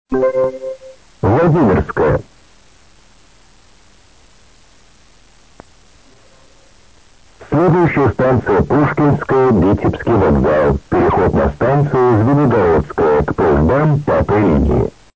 Скажу сразу, что интонации всё-таки заставили его изменить на более энергичные, чем было прежде. Голос узнаётся, но окраска вся пропала.
Несильно зажат динамический диапазон, но очень сильно заужена полоса - плоский звук как из динамика мобильника.